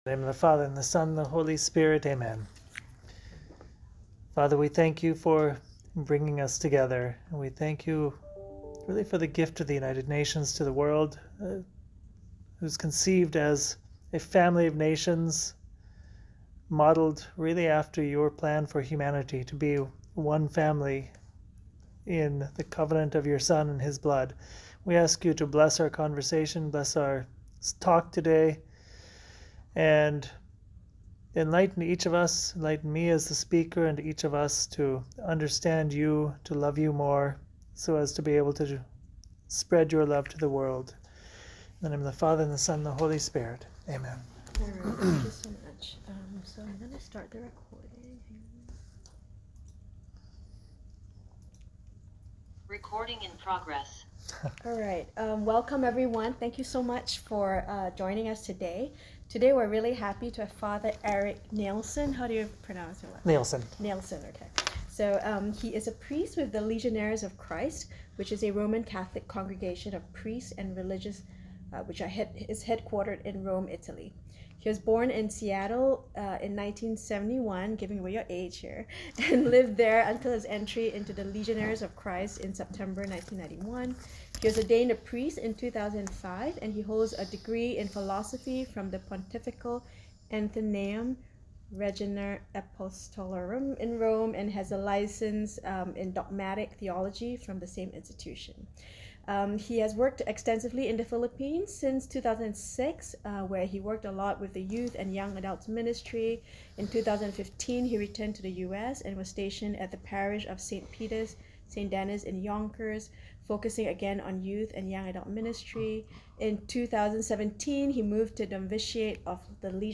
meditation to the United Nations Catholic Employees